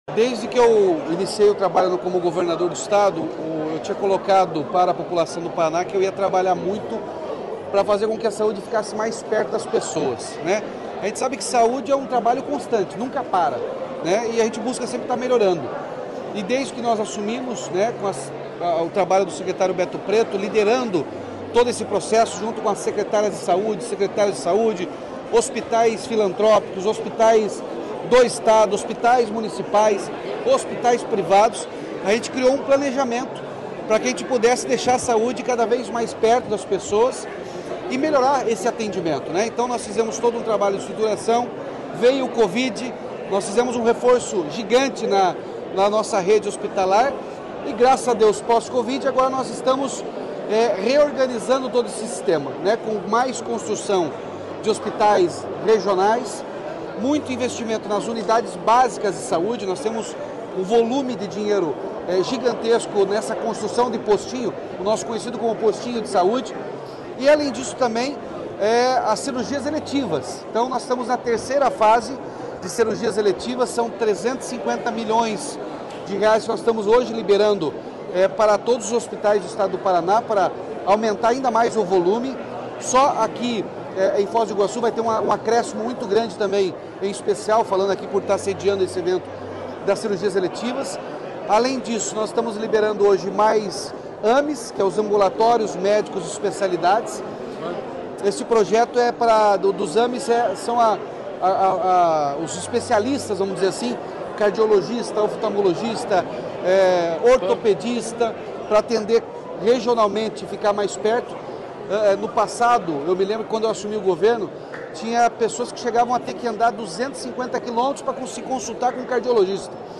Sonora do governador Ratinho Junior sobre o anúncio do maior investimento em saúde da história do Paraná